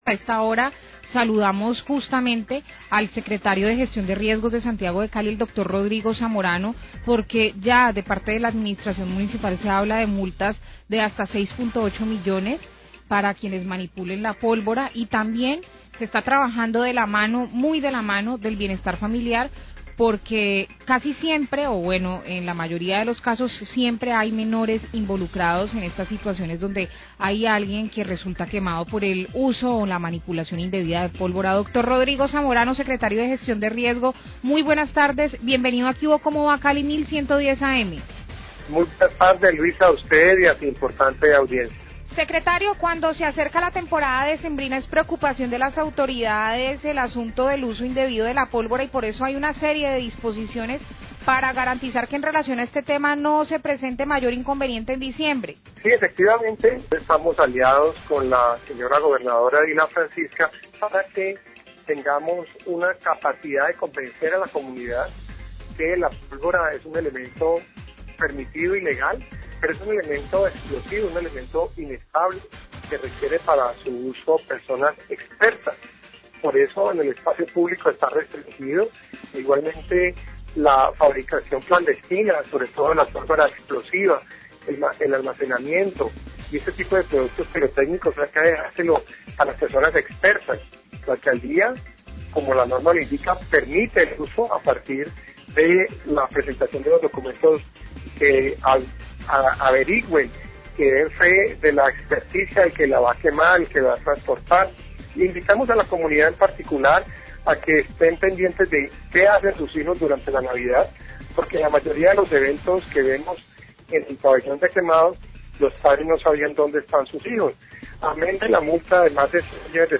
Radio
Ya se encuentran listas las restricciones para la comercialización y uso de la pólvora en Cali y regirán este fin de año y comienzos del 2017, con lo que se pretende evitar que personas resulten afectadas y la alegría de diciembre se les convierta en la tragedia del nuevo año. Rodrigo Zamorano, secretario de Gestión de Riesgo de Cali, hace referencia a este tema.